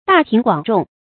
大廷廣眾 注音： ㄉㄚˋ ㄊㄧㄥˊ ㄍㄨㄤˇ ㄓㄨㄙˋ 讀音讀法： 意思解釋： 見「大庭廣眾」。